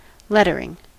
Ääntäminen
Ääntäminen US Haettu sana löytyi näillä lähdekielillä: englanti Lettering on sanan letter partisiipin preesens.